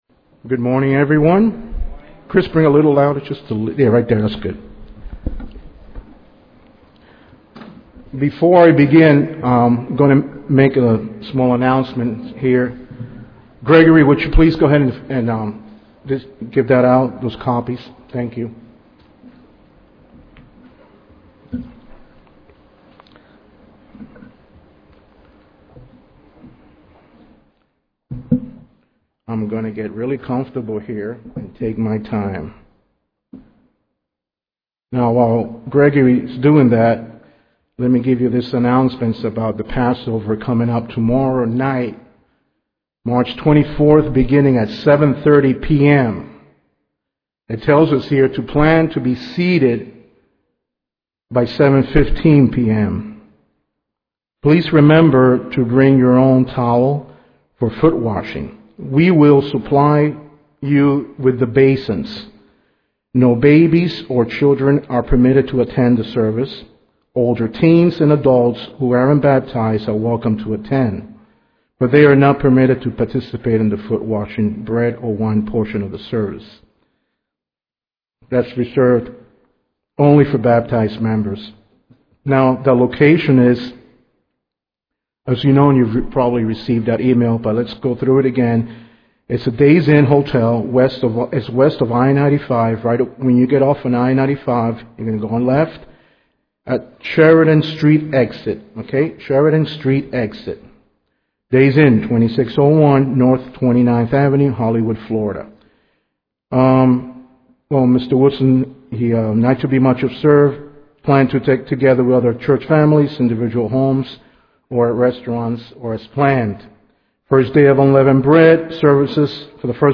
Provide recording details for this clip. Given in Ft. Lauderdale, FL